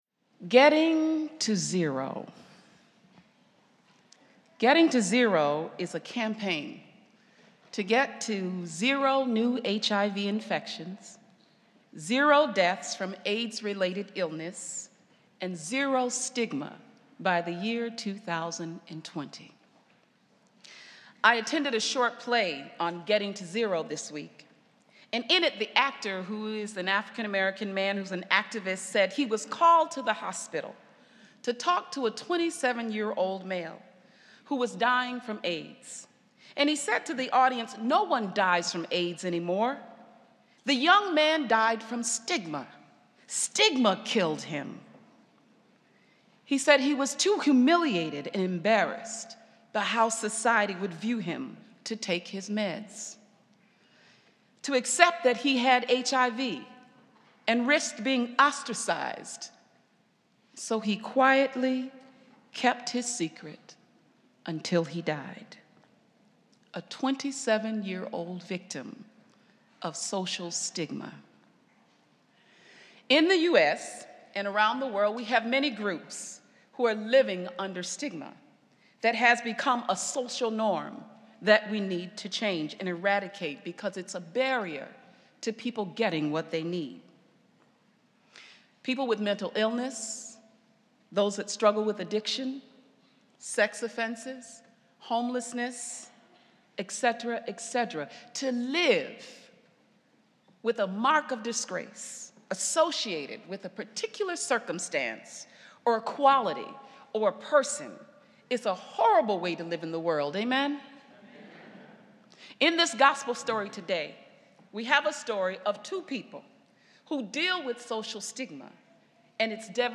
The Spiritus Choir is joined today by the Zion Hill Choir.